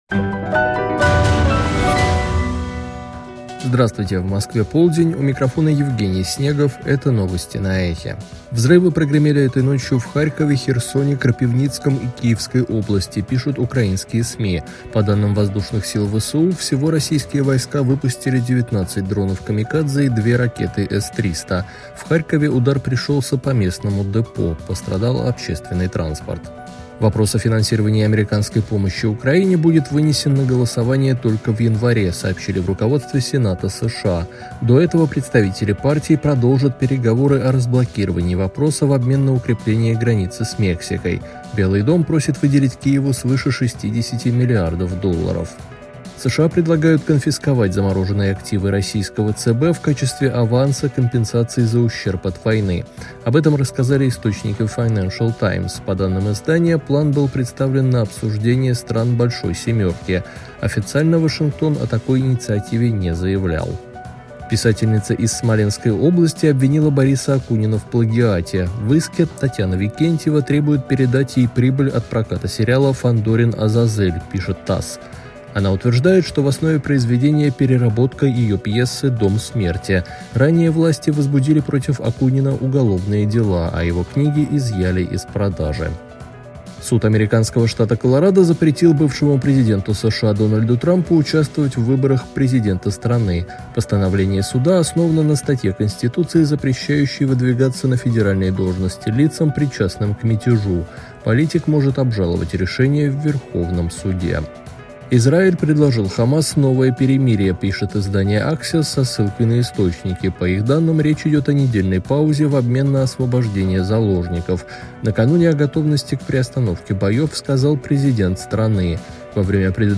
Слушайте свежий выпуск новостей «Эха»
Новости